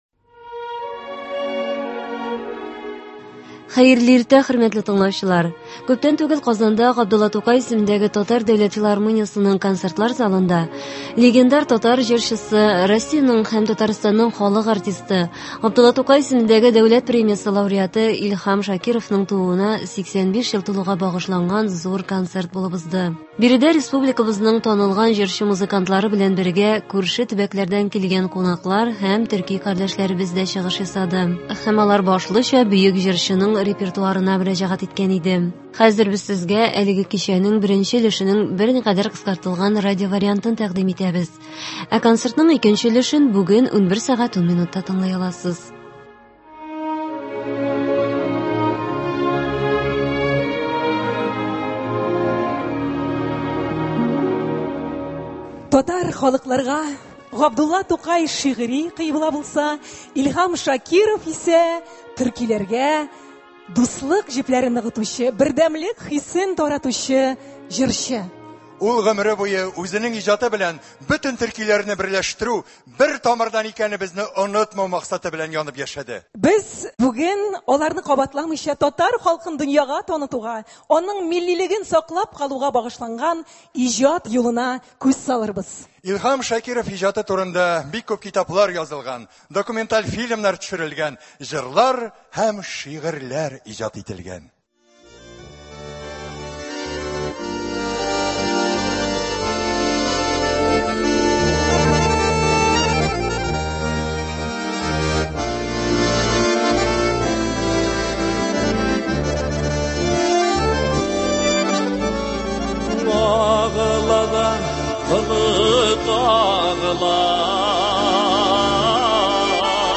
Биредә республикабызның танылган җырчы-музыкантлары белән бергә, күрше республикалардан килгән кунаклар һәм төрки кардәшләребез дә чыгыш ясады. Һәм алар башлыча бөек җырчының репертуарына мөрәҗәгать иткән иде. Без сезгә әлеге кичәнең берникадәр кыскартылган радиовариантын тәкъдим итәбез.